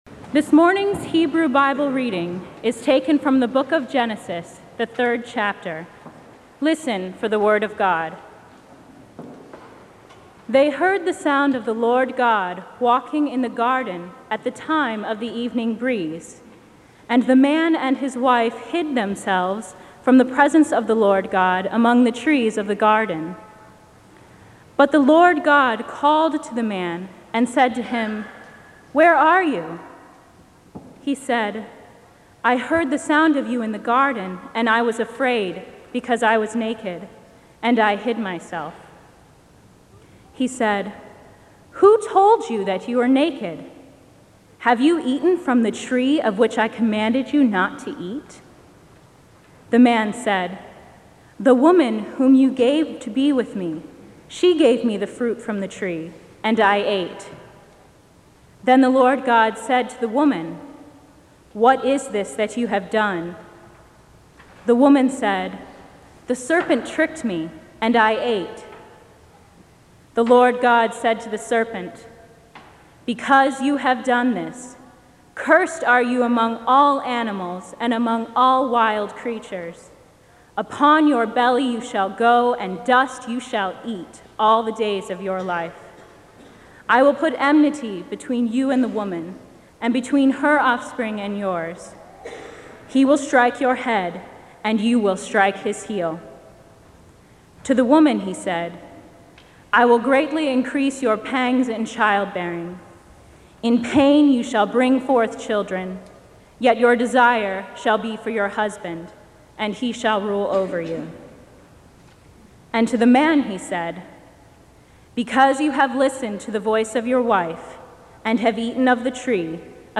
A sermon delivered by the Rev. Peter J. Gomes at Marsh Chapel at Boston University.